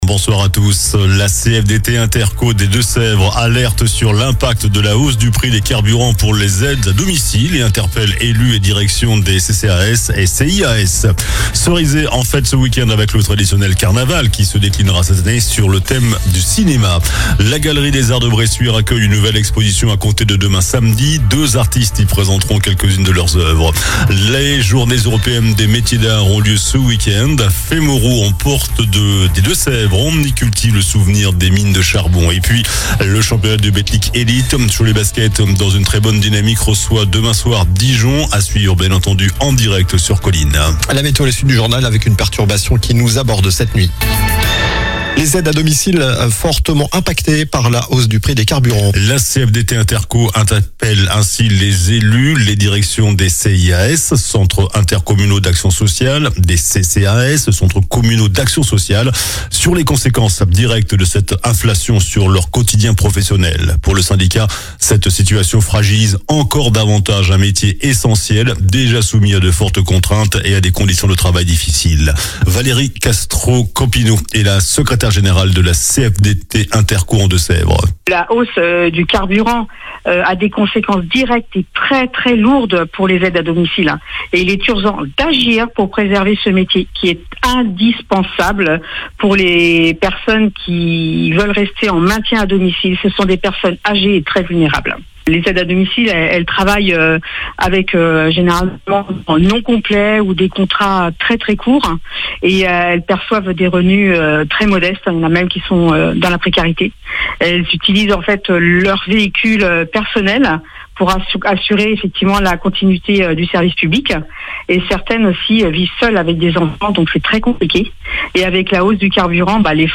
JOURNAL DU VENDREDI 10 AVRIL ( SOIR )